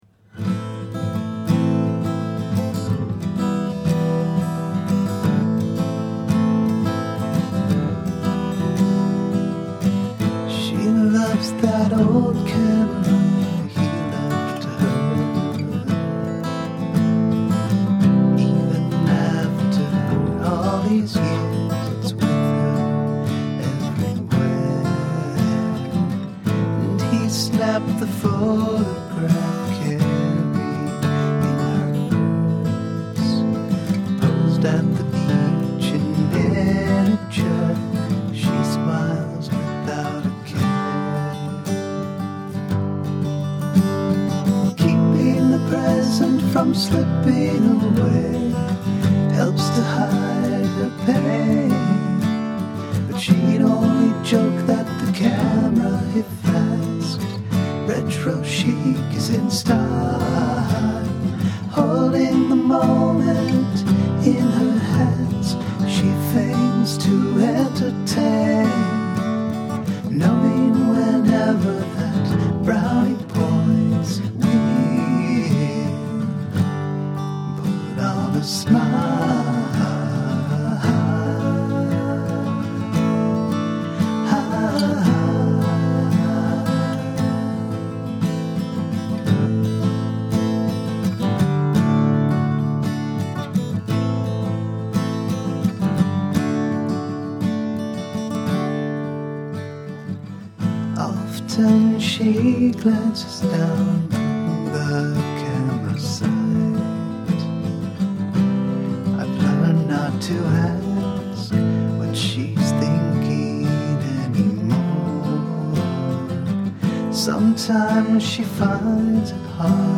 Time Signature Changes